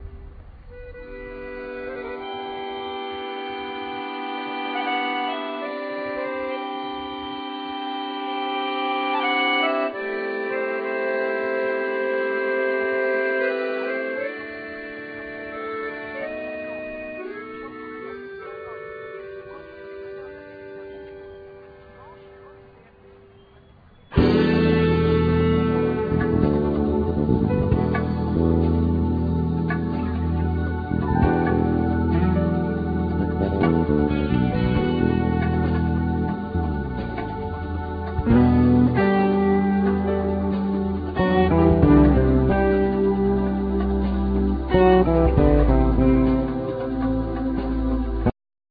Bass
Trumpet
Keyboard
Guitar
Fretless Bass
Drums
Percussions
Accordion
Trombone
Alto saxophone